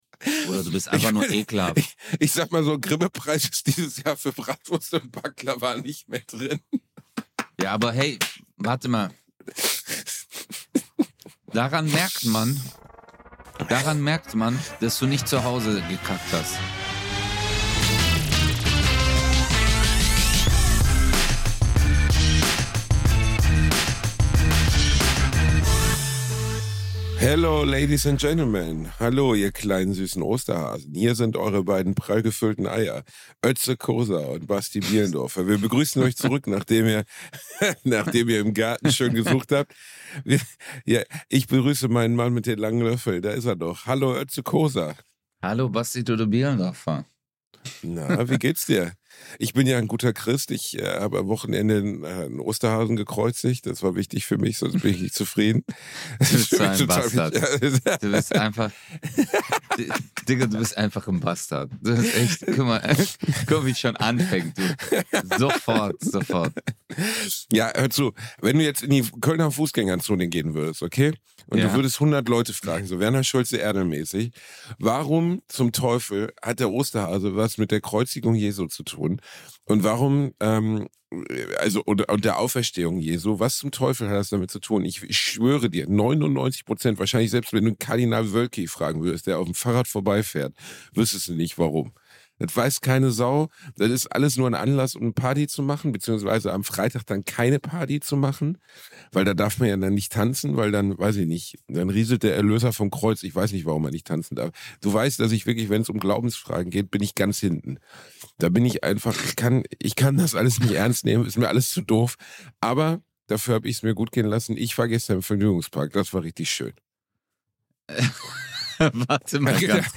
Sie sprechen über Weltstars, Freizeitparks und wichtige Feiertage in der Türkei. Ihr hört Bastis Achterbahnschreie bei Todesangst und bekommt Lifehacks für verstopfte Toiletten und weitere Probleme.